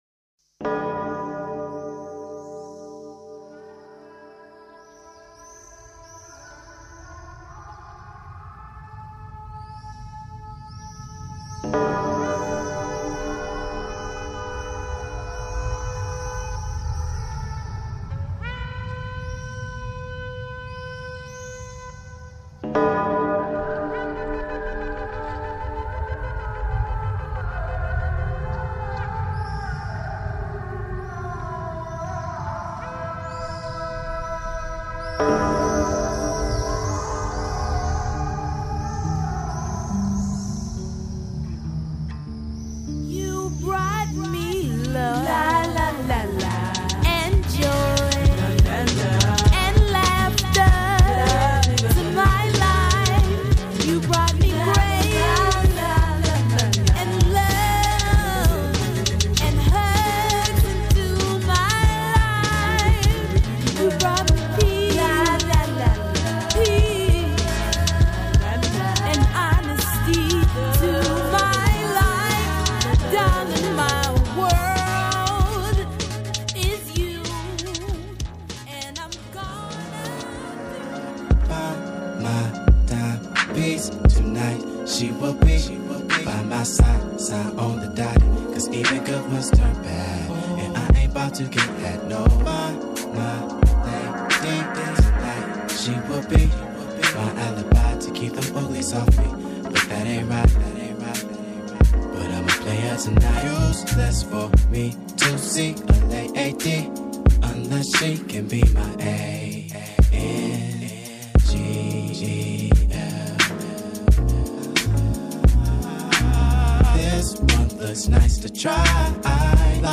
Here she is sharing a special mix made for Valentine’s Day.
Beats Disco/House Electronic International Synth